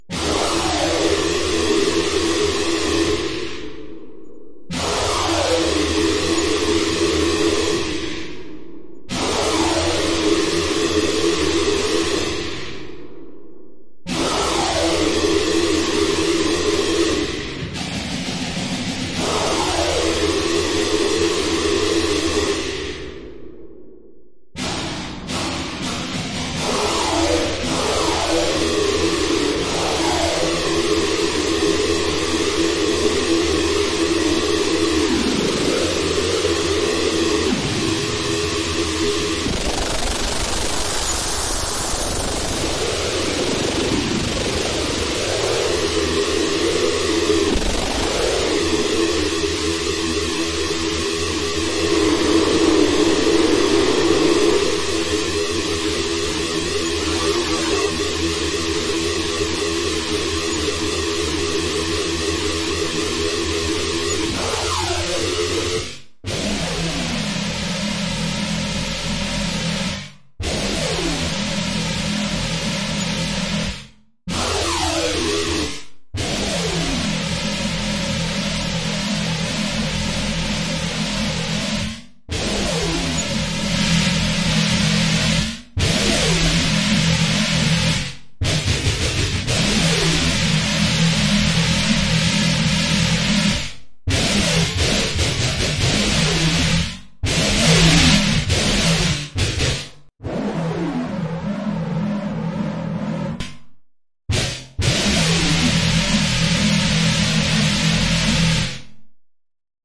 胡言乱语的人
描述：我自己制作的录音模仿了我如何想象一个来自“龙与地下城”的笨拙的Mouther听起来像。
标签： 可怕 Mouther 效果 可怕的 自语 幻想 录音 功效 地牢 FX 免打扰 声音
声道立体声